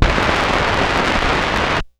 Record Noises
Record_End_Paper.aif